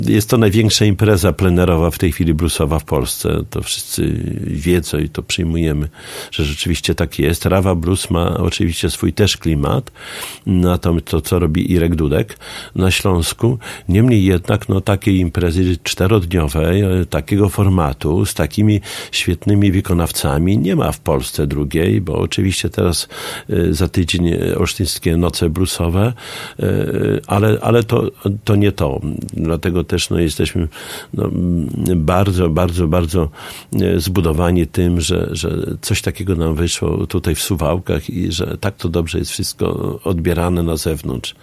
Imprezę podsumował w poniedziałek (10.07) w Radiu 5 Czesław Renkiewicz, prezydent Suwałk.